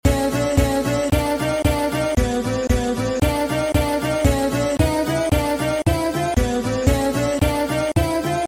Part 2 Zozobra Roar Effect sound effects free download